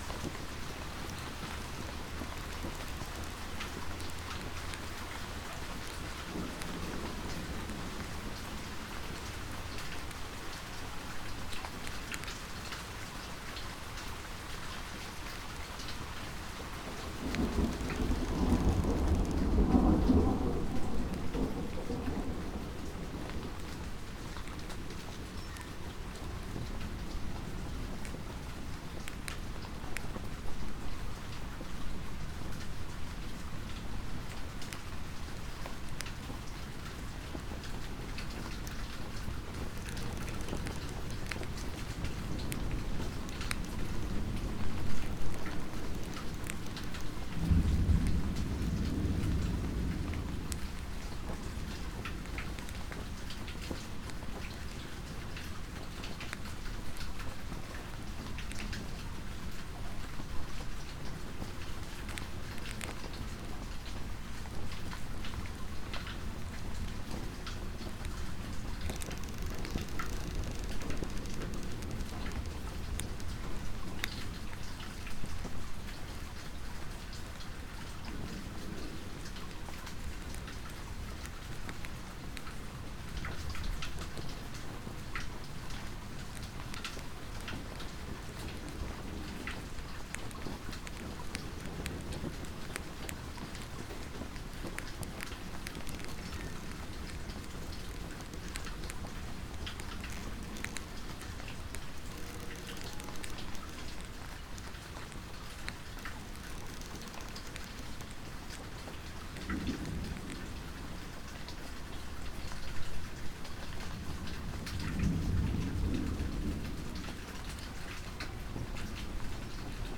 RainonWatchtower.ogg